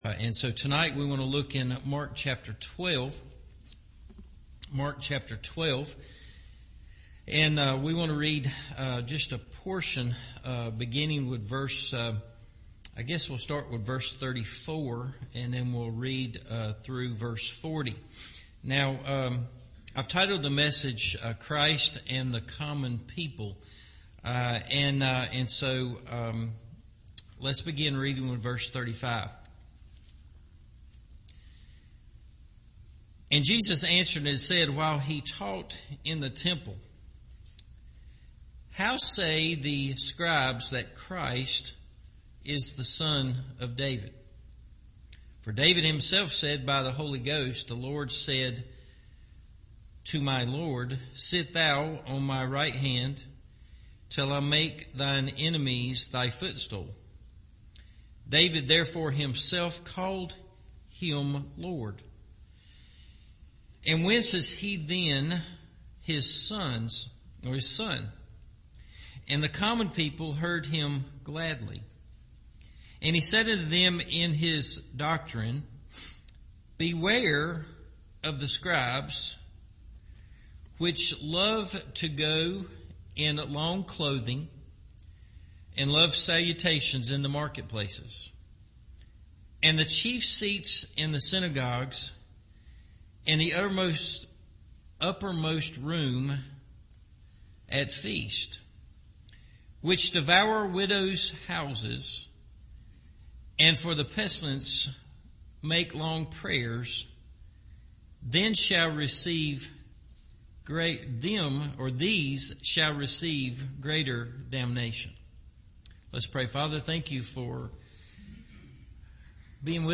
Just Common People – Evening Service